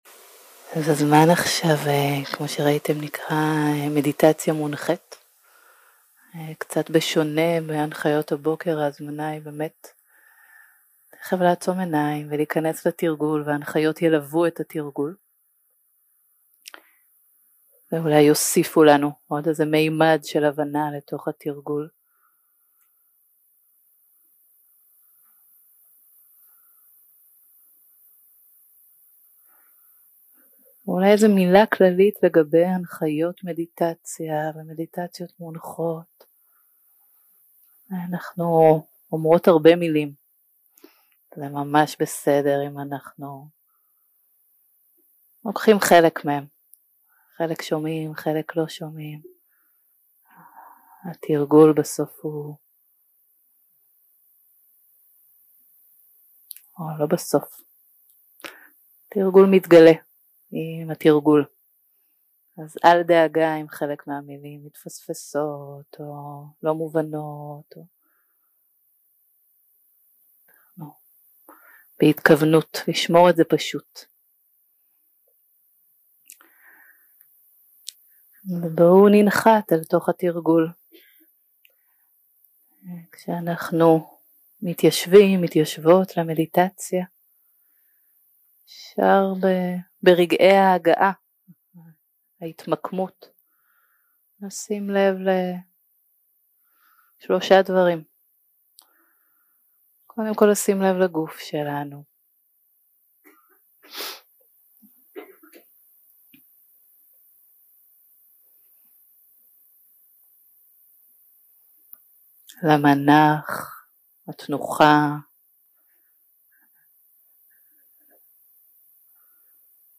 סוג ההקלטה: מדיטציה מונחית שפת ההקלטה